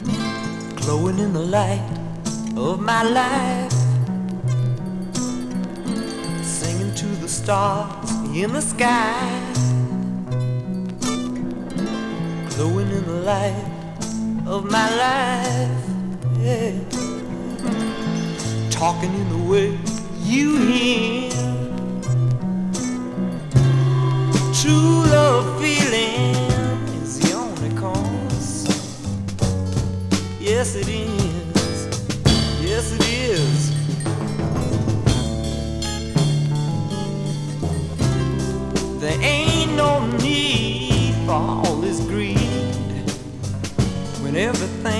サウンドは荒々しく、まさにダイナマイト。実験的とも思えるサイケデリックさも有り。
Rock, Garage, Psychedelic　USA　12inchレコード　33rpm　Stereo